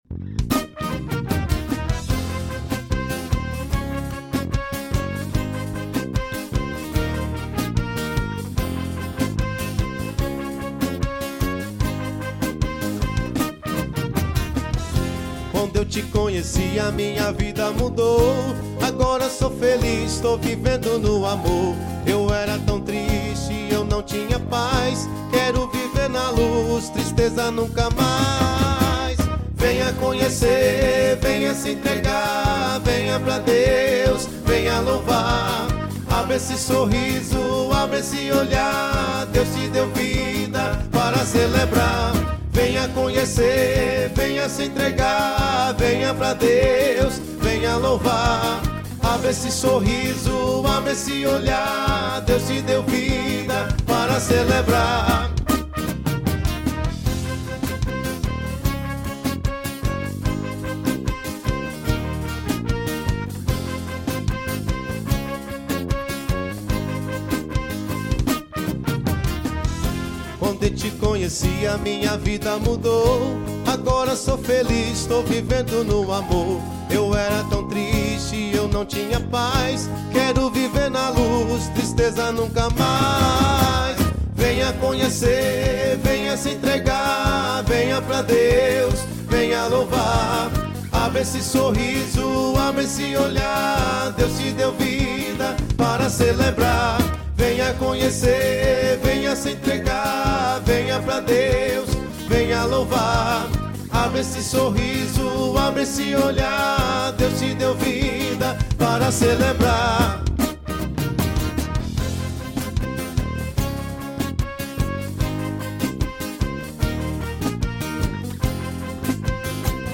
Gênero Católica.